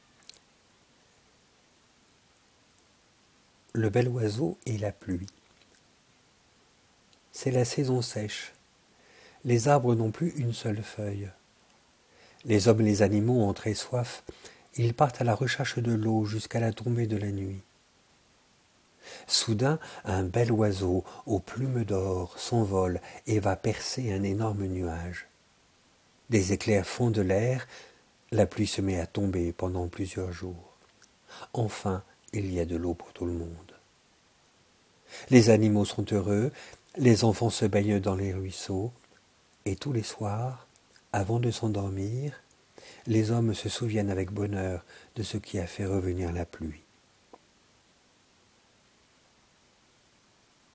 Elle permettra aux enseignants, orthophonistes, rééducateurs, psychologues ou chercheurs d’évaluer le niveau de compréhension d’enfants de 5 à 8 ans qui écoutent la lecture à haute voix de trois récits non illustrés (cf. les parties I et II du document de présentation de l'épreuve et les enregistrements sonores).